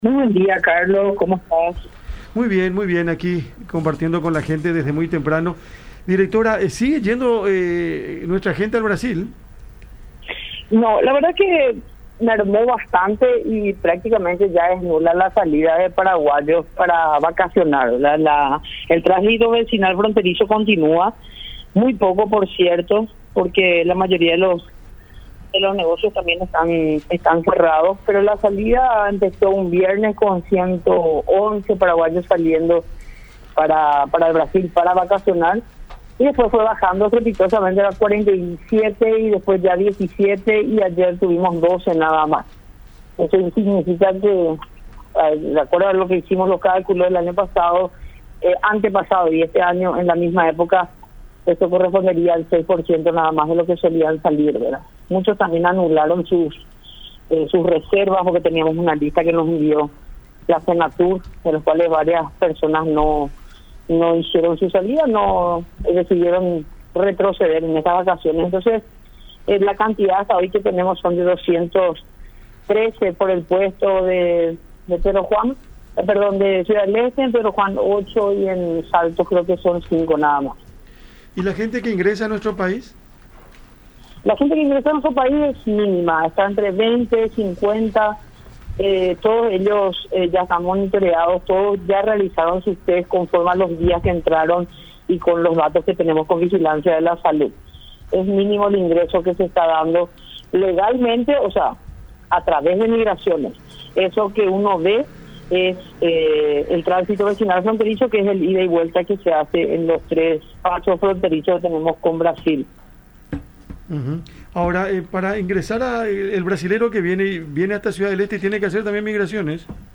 “Mermó bastante, prácticamente es nula la salida de paraguayos para vacaciones al Brasil. El muy poco tránsito vecinal fronterizo continúa”, dijo Ángeles Arriola, titular de Migraciones, en conversación con La Unión.